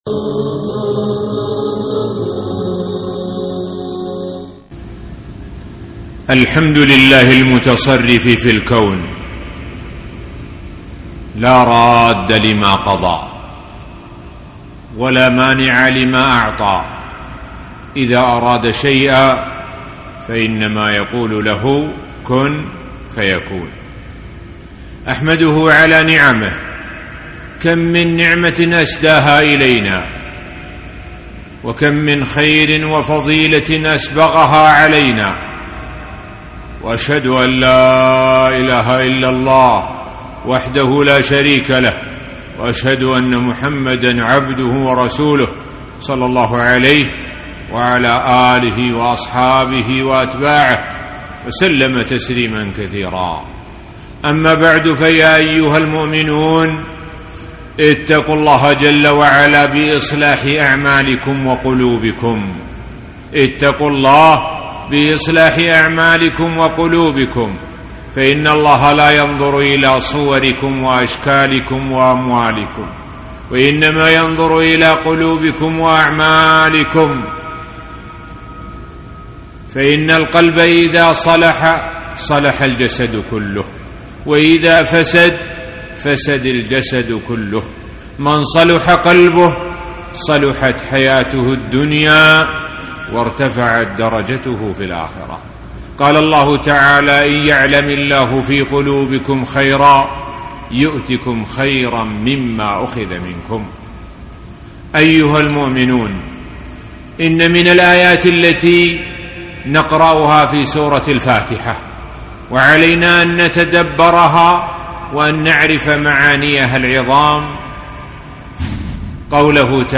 خطبة - التوكل على الله